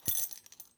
foley_keys_belt_metal_jingle_15.wav